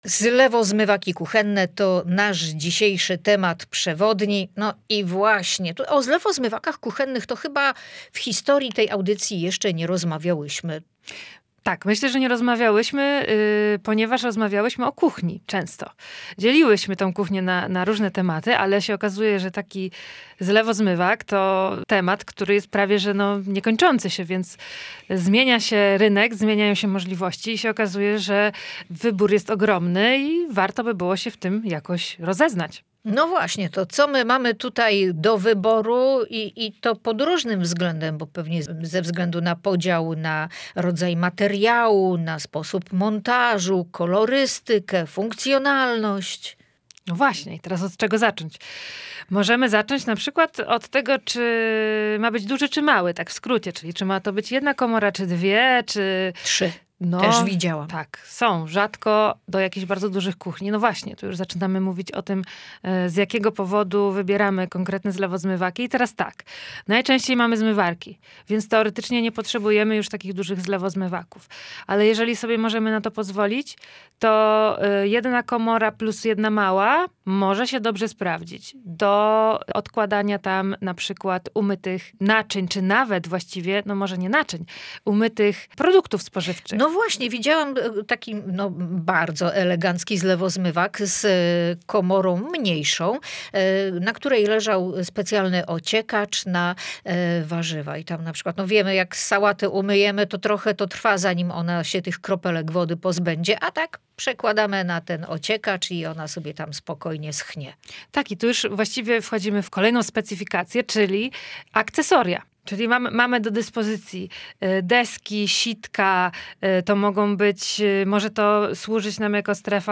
archiwum audycji